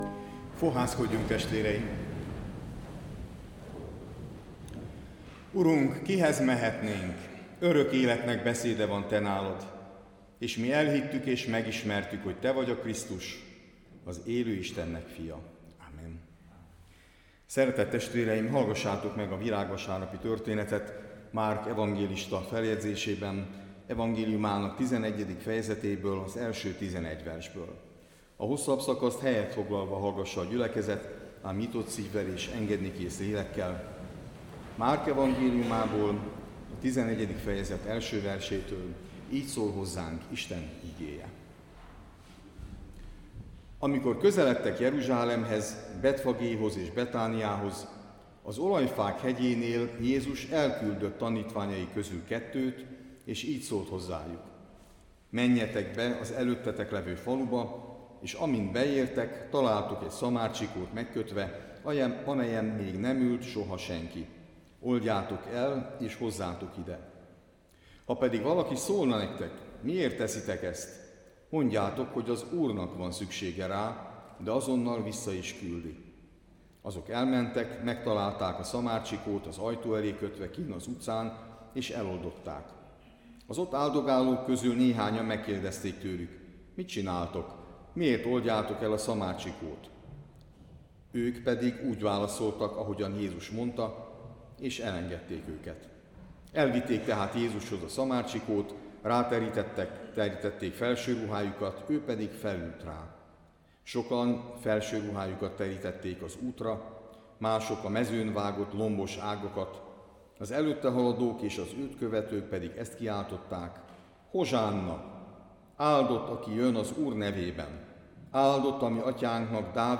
(Márk 11,10) (A felvétel Szabadszálláson készült) Letöltés Letöltés